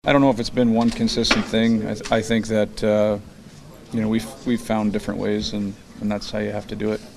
Crosby says they’ve had to do it in a variety of ways.